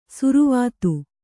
♪ suruvātu